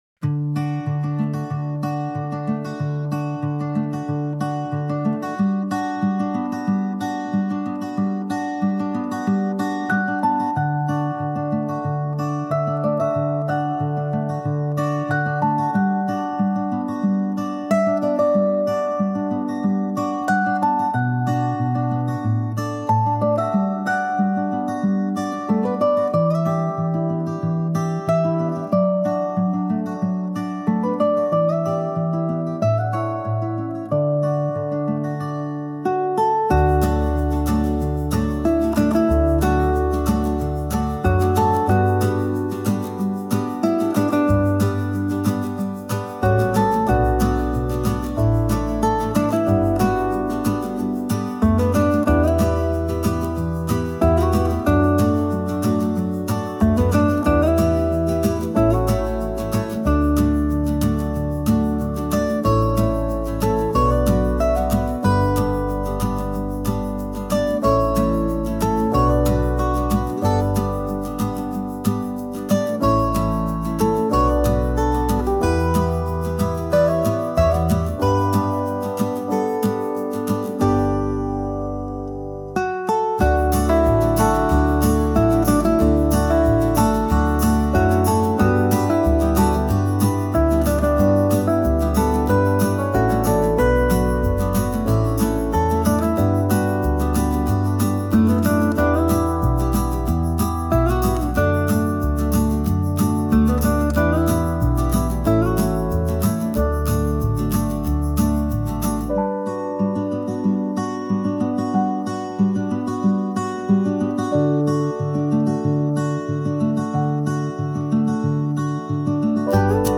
著作権フリーオリジナルBGMです。
インストゥルメンタル
ポップス アコースティック 明るい 優しい